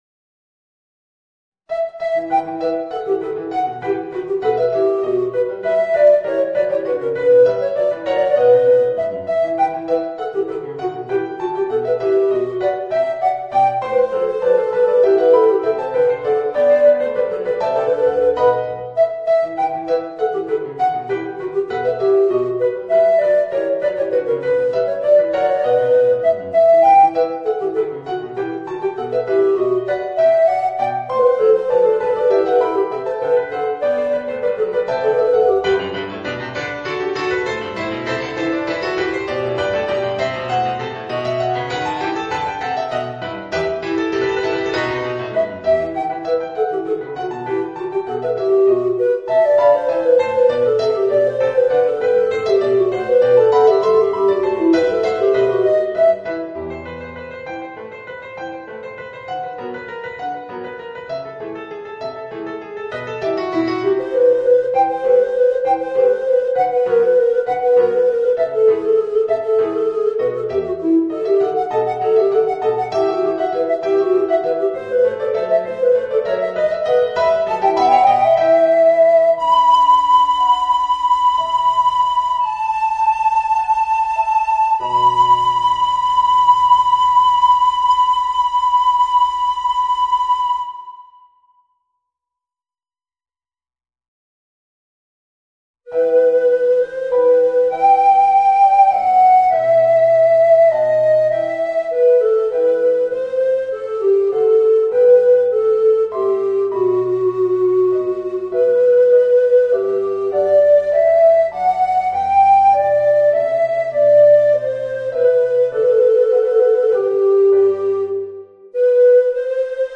Voicing: Soprano Recorder and Piano